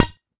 cue_hit01.wav